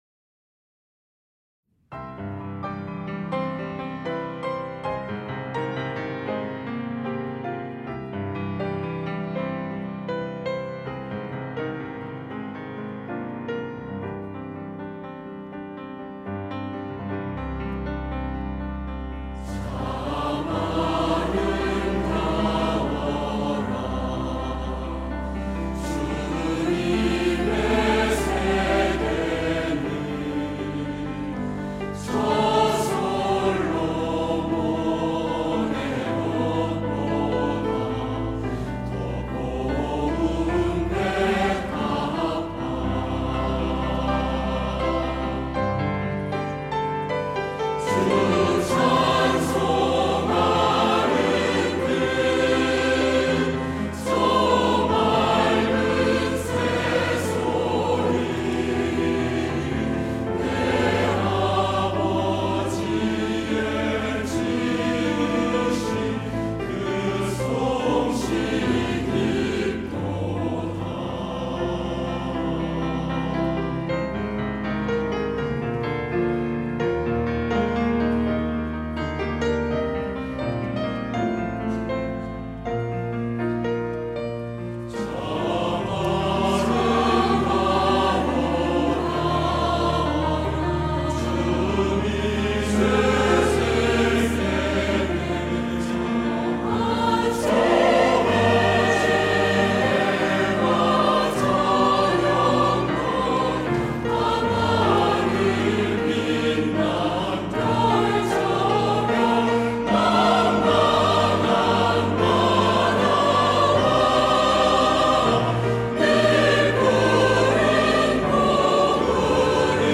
할렐루야(주일2부) - 참 아름다워라
찬양대